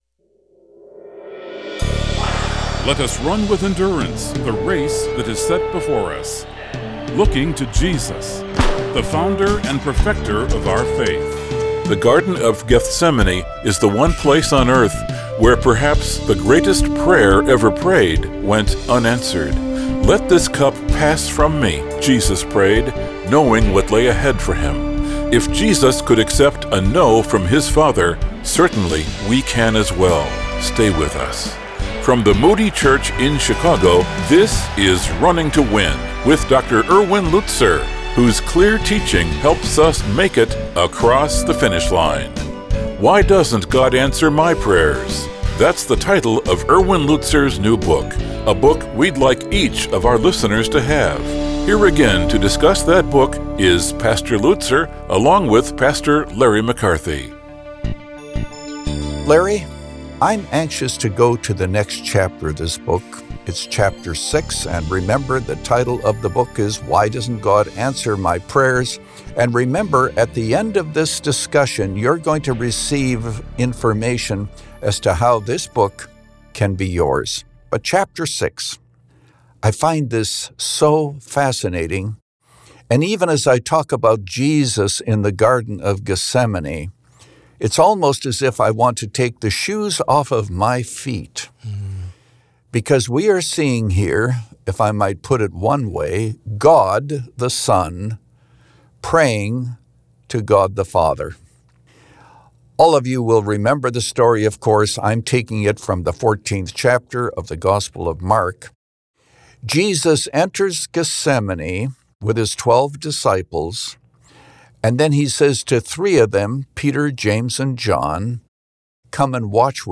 In this interview